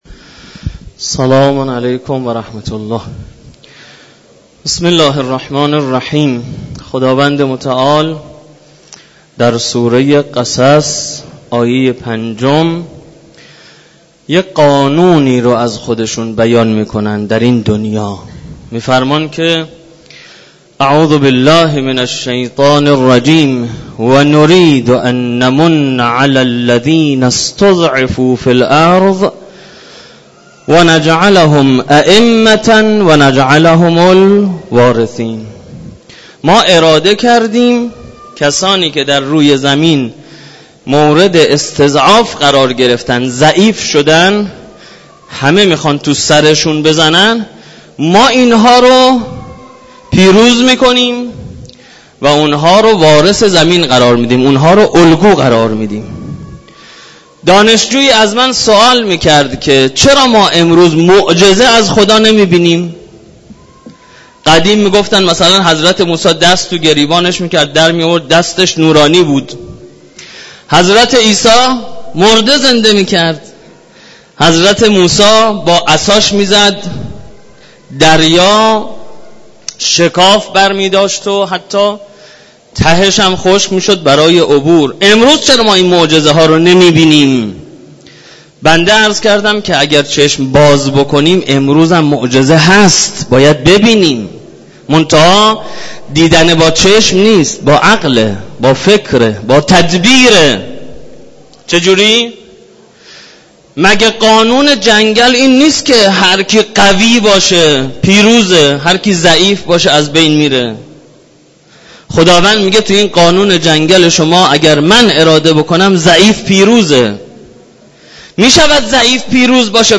سخنرانی
در مسجد دانشگاه کاشان در تفسیر آیه ۵ سوره مبارکه قصص